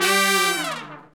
Index of /90_sSampleCDs/Roland L-CDX-03 Disk 2/BRS_R&R Horns/BRS_R&R Falls